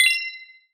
Game Notification 83.wav